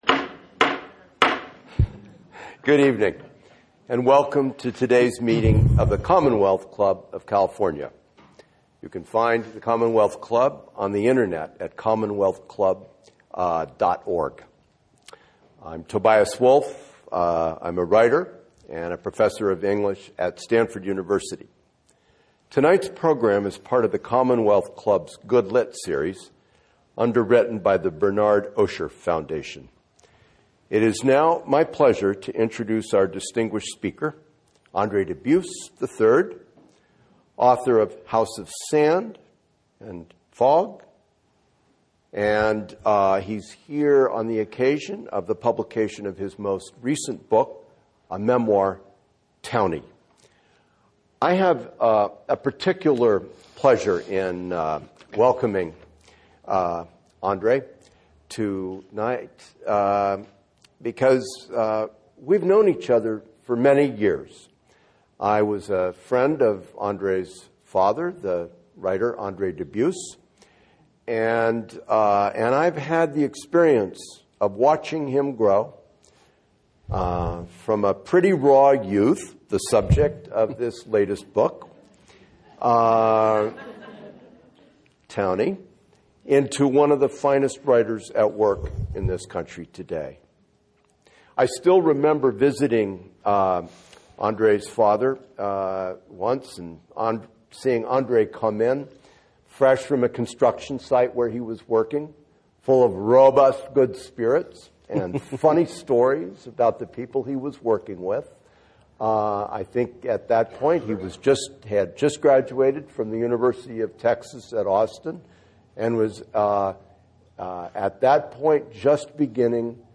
Andre Dubus in Conversation with Tobias Wolff